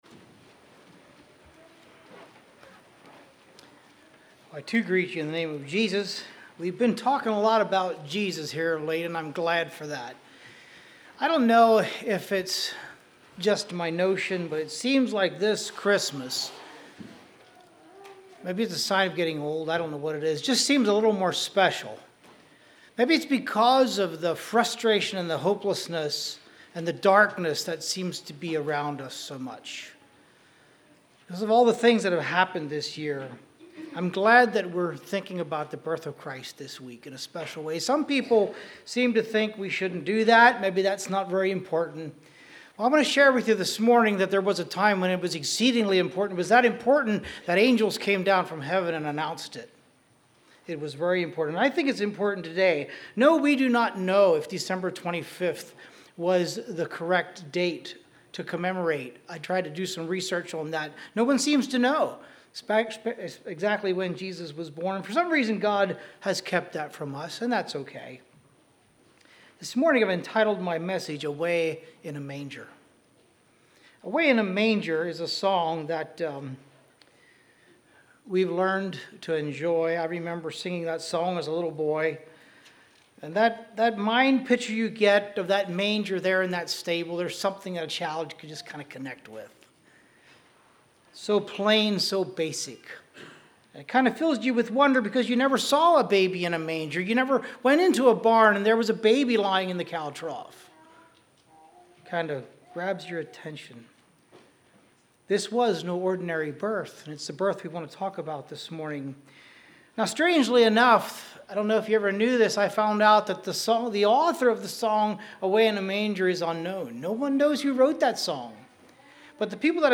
From being born in a manger to dying on the cross Jesus gave us a perfect example of how to live a humble life. This sermon contains nine significant facts about the manger in which Christ was laid.